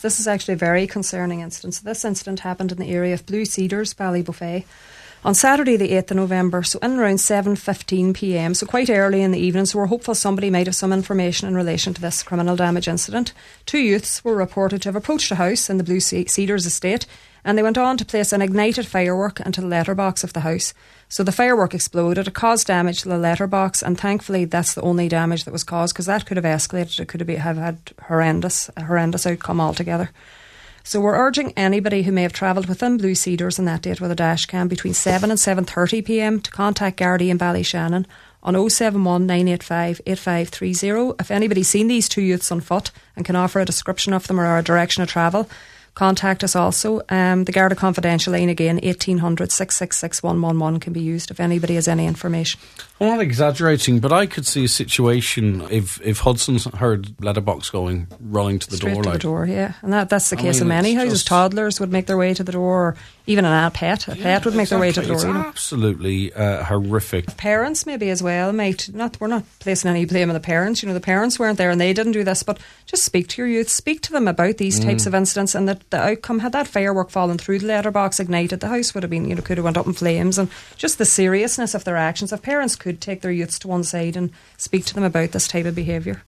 made this appeal for information on this morning’s Nine ‘til Noon Show: